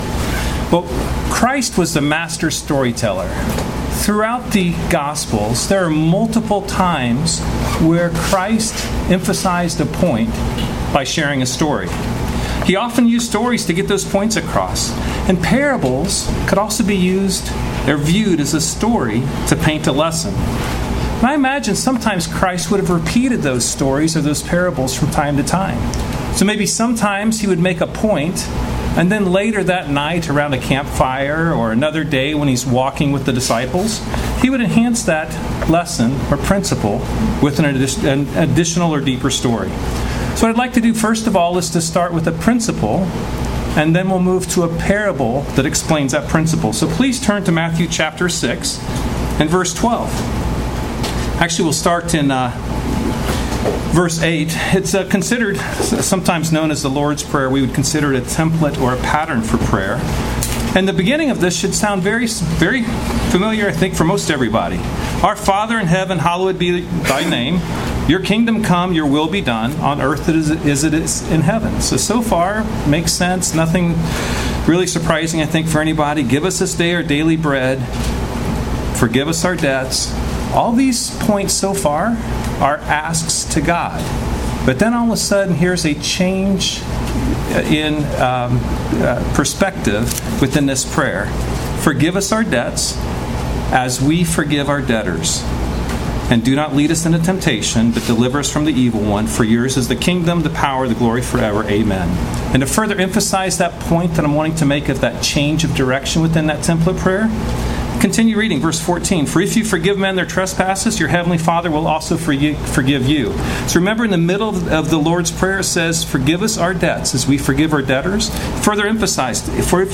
Sermons
Given in Cincinnati North, OH